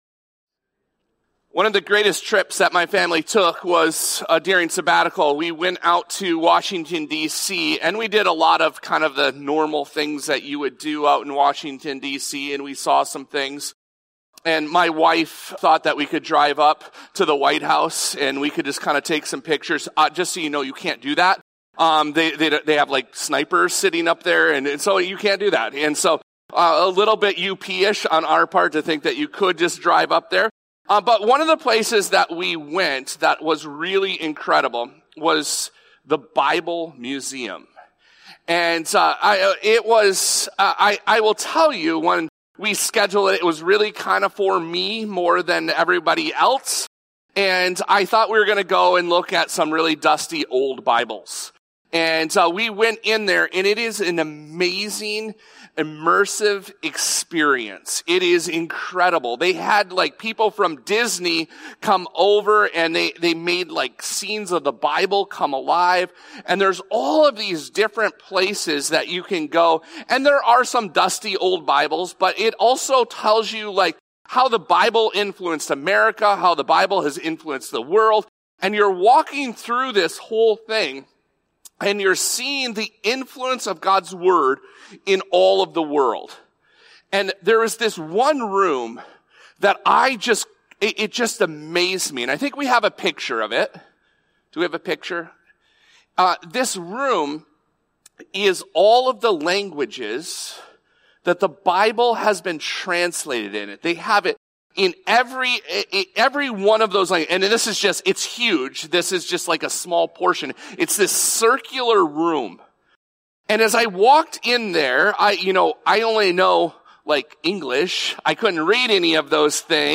This episode of the Evangel Houghton podcast is a Sunday message from Evangel Community Church, Houghton, Michigan, January 11, 2026.